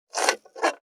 481,厨房,台所,野菜切る,咀嚼音,ナイフ,調理音,まな板の上,料理,
効果音厨房/台所/レストラン/kitchen食器食材